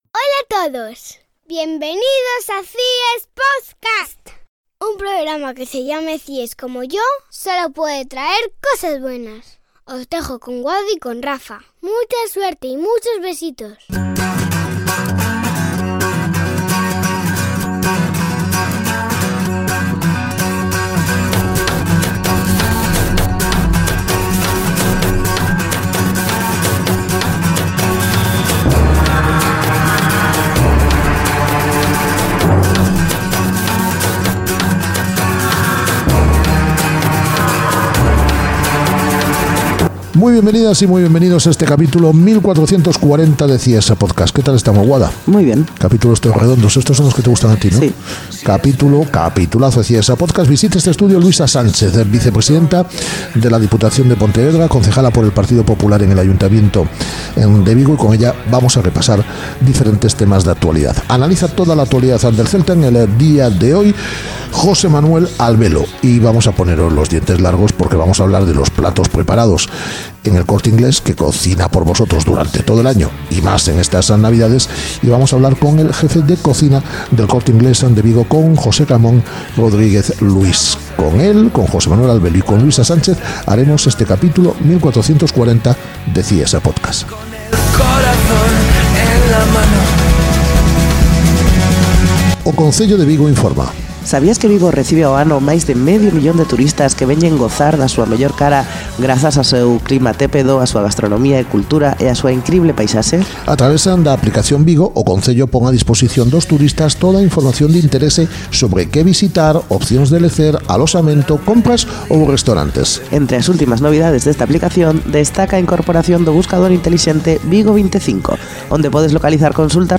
Entrevista con la vicepresidenta de la Deputación de Pontevedra y concejala del Partido Popular en Vigo, Luisa Sánchez.